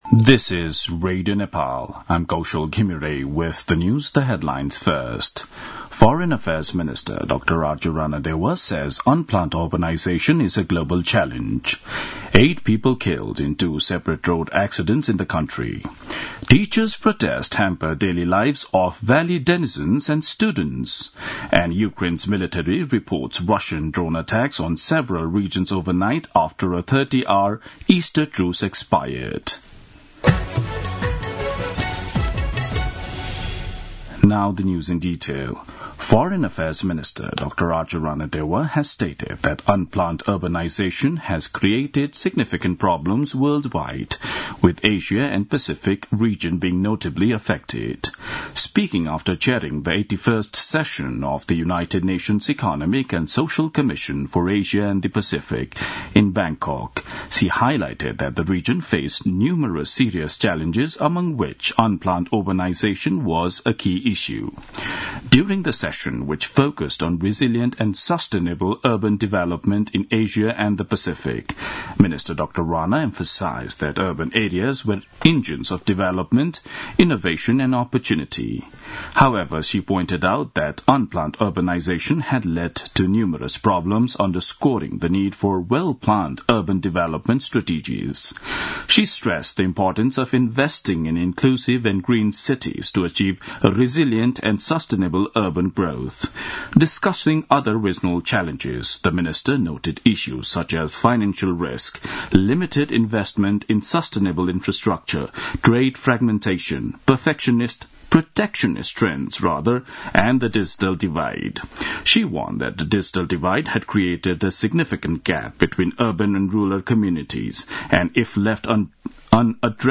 दिउँसो २ बजेको अङ्ग्रेजी समाचार : ८ वैशाख , २०८२